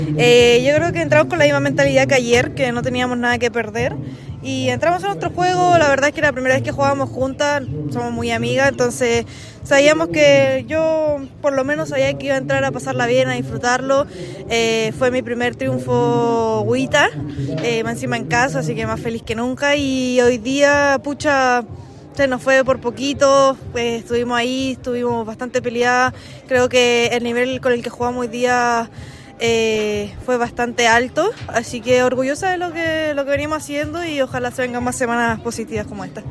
Tras los encuentros, ambas dialogaron con Chicureo Hoy y entregaron sus sensaciones.